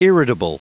Prononciation du mot irritable en anglais (fichier audio)
Prononciation du mot : irritable